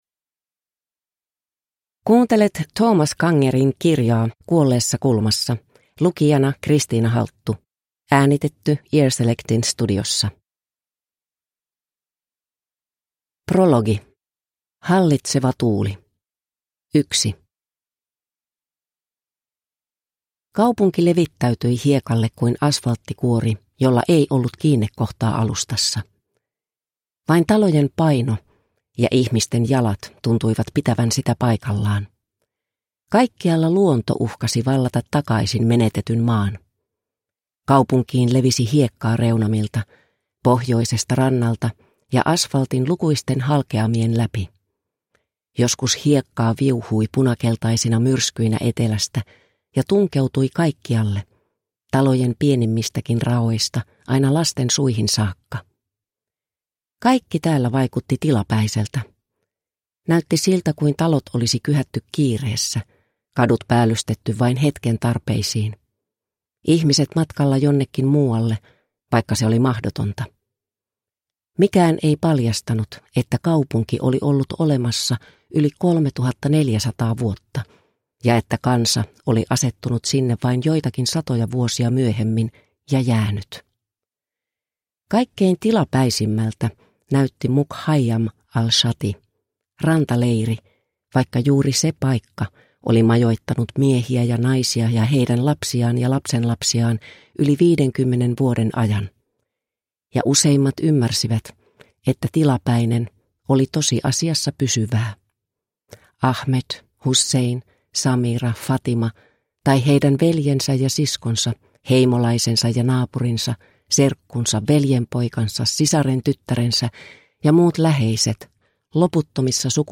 Kuolleessa kulmassa (ljudbok) av Thomas Kanger